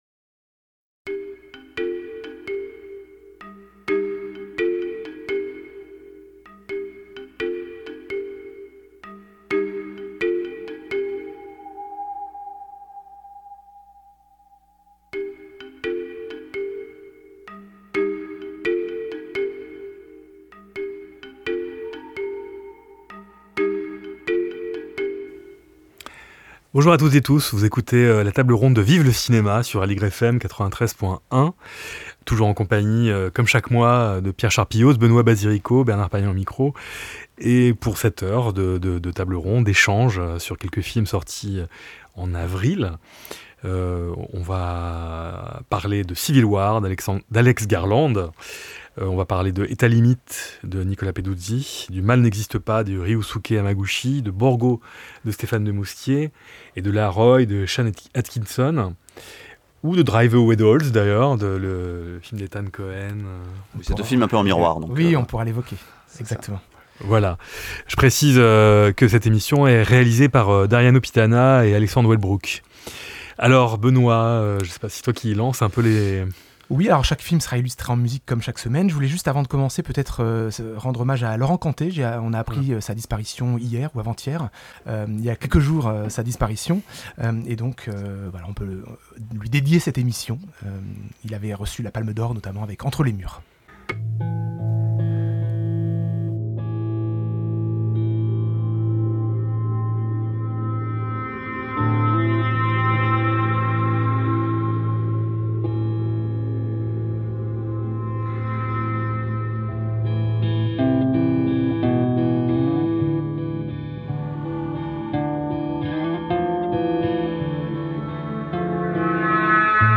Table ronde critique autour des films :